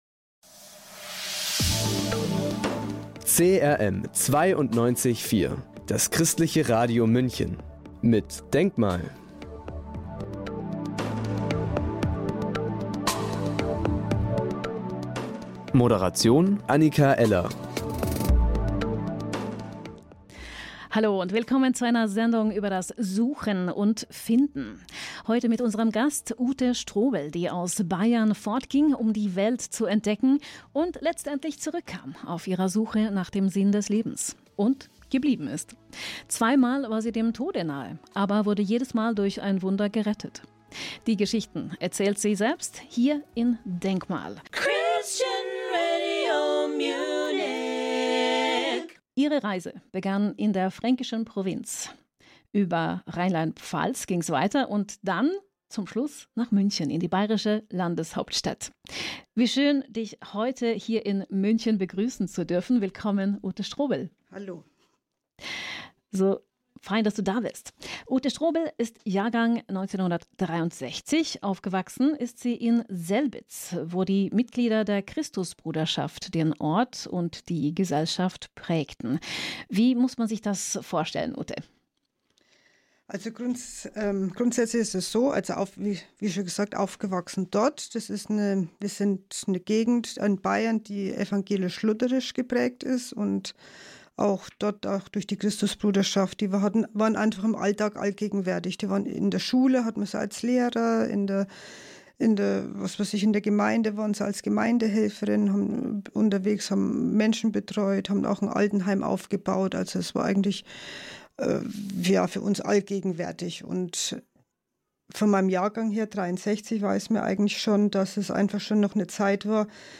Zweimal war sie dem Tod nahe, aber wurde jedes Mal durch ein Wunder gerettet. Die Geschichten erzählt sie selbst in dieser DENKmal-Sendung. Die Bibelzitate stammen aus Psalm 33,4 und Römerbrief 8,38.